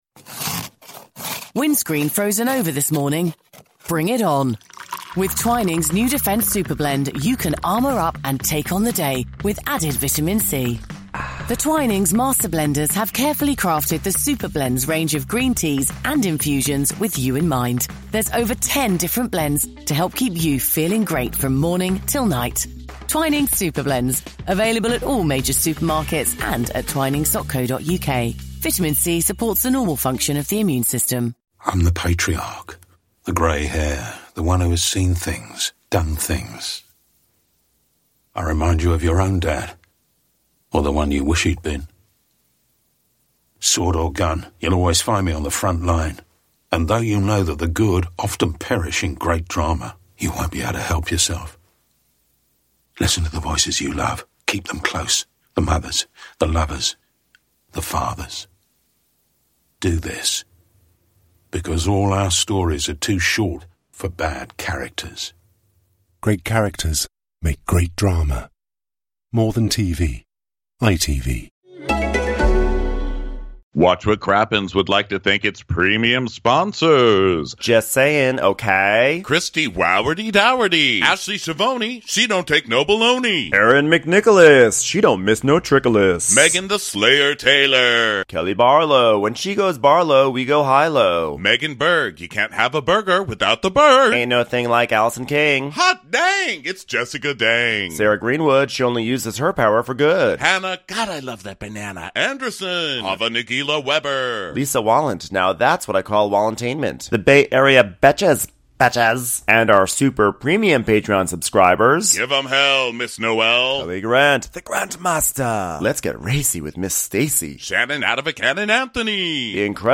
We took our show to "Just For Laughs" in Vancouver this week, and what better way to make a splash in Canada than by recapping the Real Housewives of New Jersey reunion part 1.